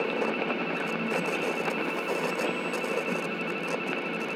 phone_hacking_static_01_loop.wav